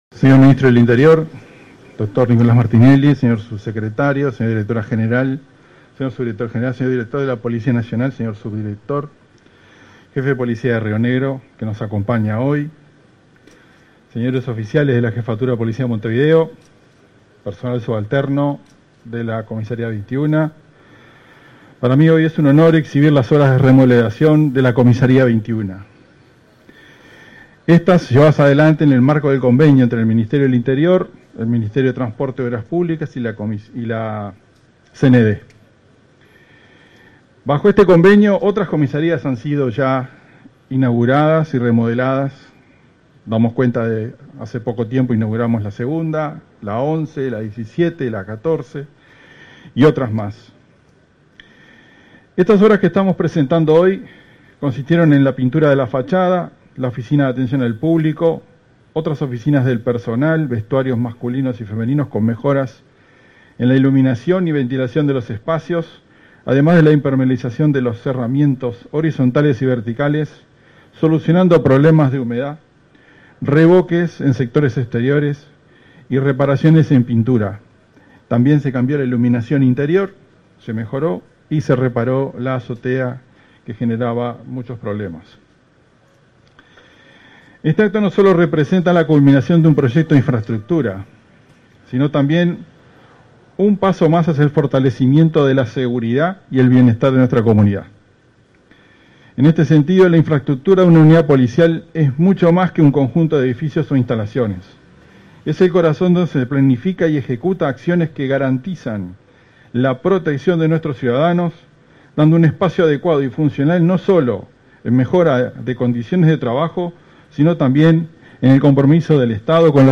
Palabras del jefe de Policía de Montevideo, Mario D´Elía
En el marco de la presentación de obras de reforma de la seccional n.° 21, este 14 de febrero, se expresó el jefe de Policía de Montevideo, Mario D